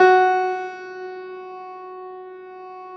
53g-pno12-F2.wav